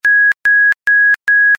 Bleepy Sound